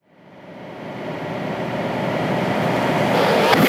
VEC3 Reverse FX
VEC3 FX Reverse 28.wav